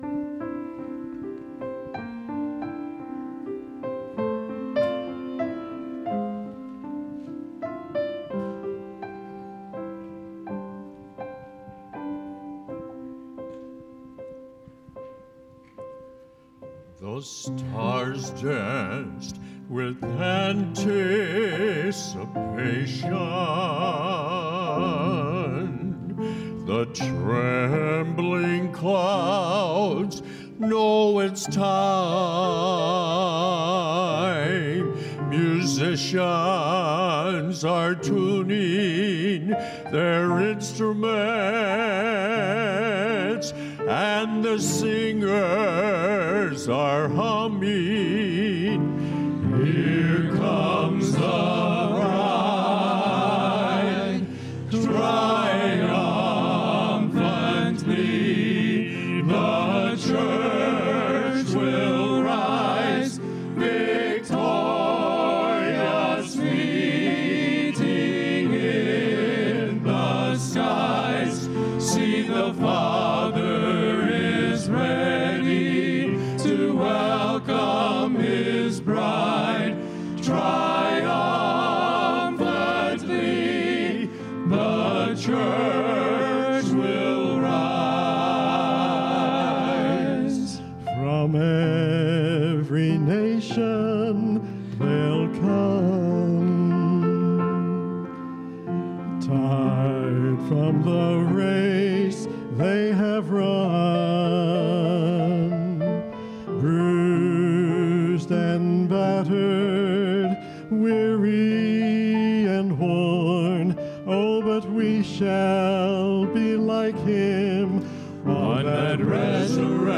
“Triumphantly The Church Will Rise” ~ Faith Baptist Men’s Quintet